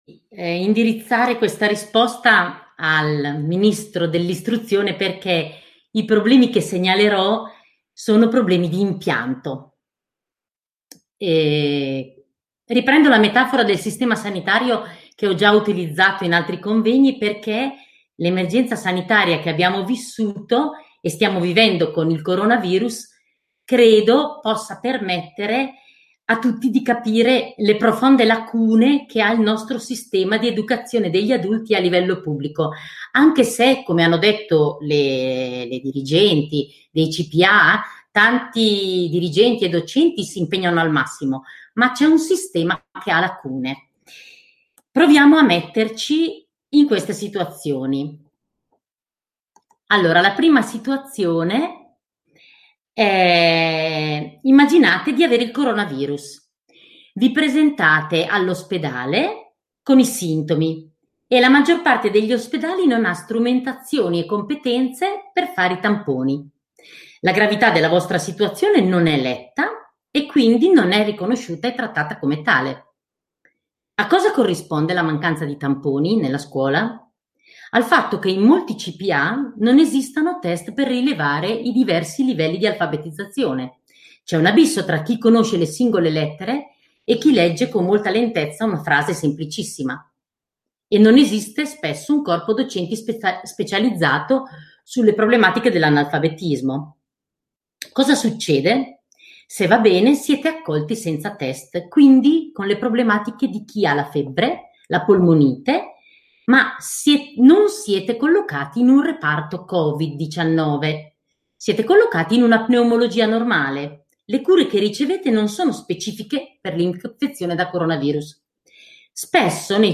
800 persone iscritte da tutt’Italia e dall’estero per assistere al webinar sull’Istruzione degli Adulti a bassa scolarità.